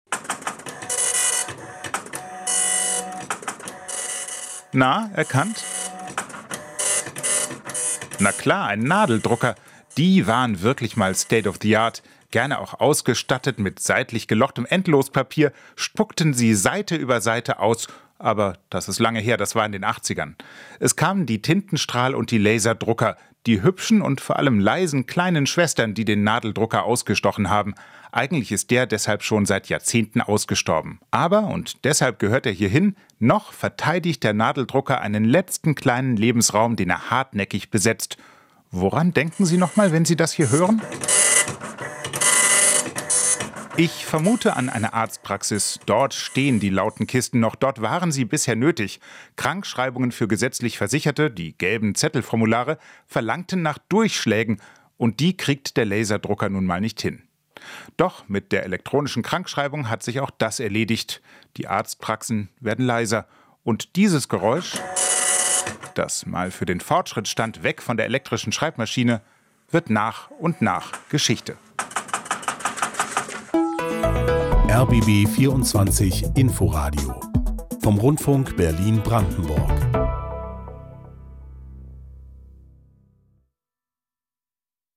Geräusche, die verschwinden: Der Nadeldrucker
verschwinden sie nach und nach. Dazu gehört auch der Nadeldrucker.